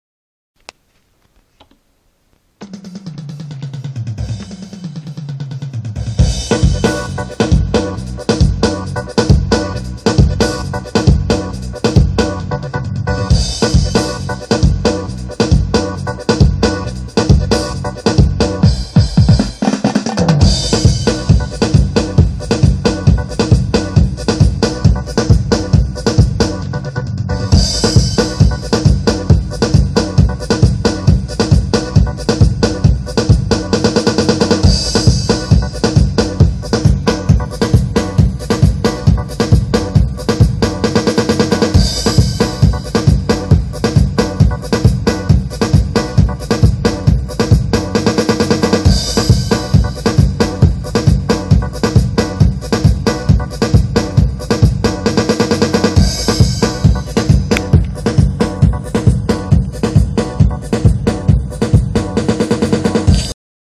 or700 için yeni ritim 2 mp3 demo
üstadlar bu edit degil sadece c nin atagını yazmadım rht 1 ve rht2 kısmı sıfırdan yazdım halay ritmii isteyen olursa paylasaırım 40 sn den sonrayı dinleyin